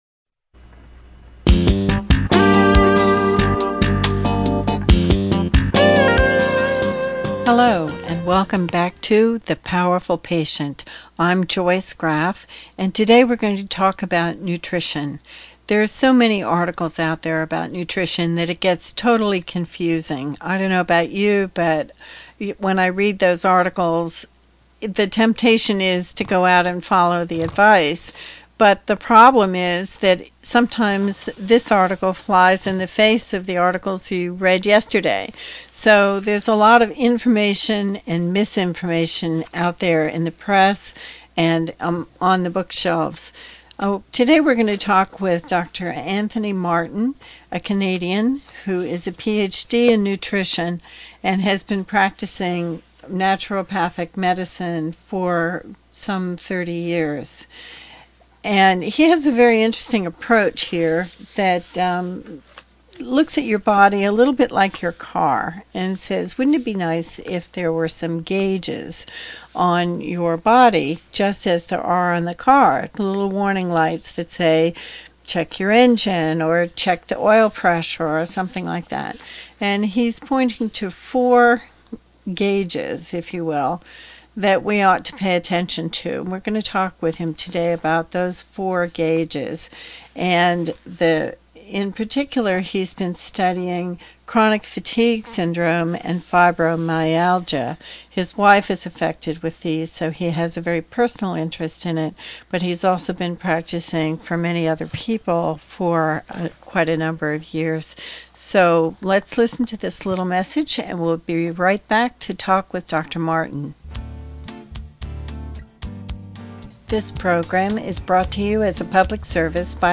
a nutritionist and natural medicine physician.